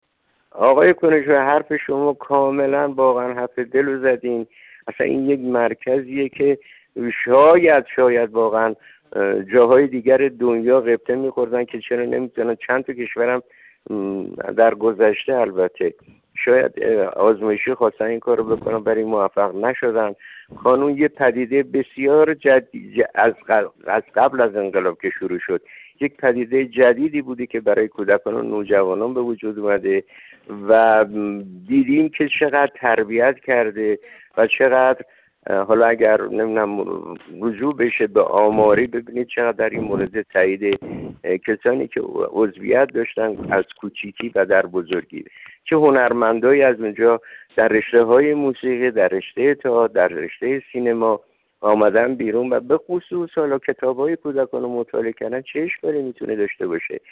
ابراهیم فروزش، کارگردان سینما و تلویزیون در گفت‌وگو با ایکنا با بیان اینکه خود را پرورش یافته کانون پرورش می‌داند، گفت: سال‌هاست از کانون بازنشسته شده‌ام اما هیچ چیز از تعلق خاطر من به این مرکز کم نشده است.